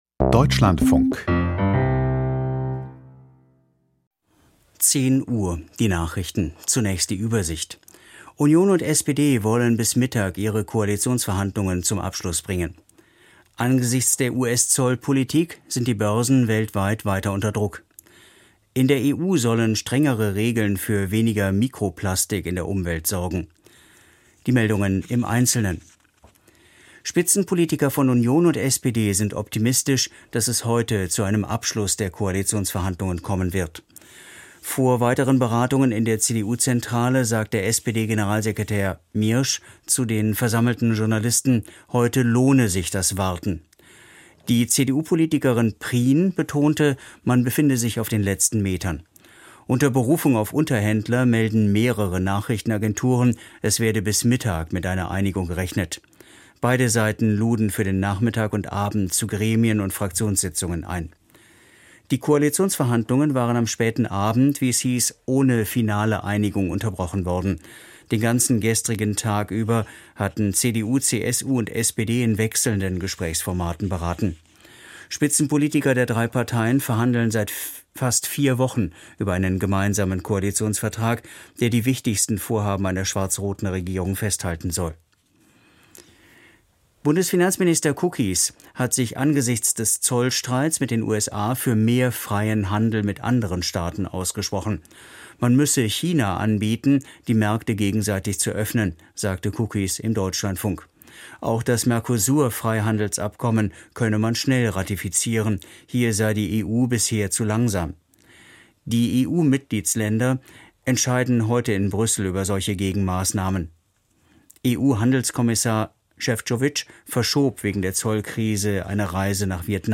Die Deutschlandfunk-Nachrichten vom 09.04.2025, 10:00 Uhr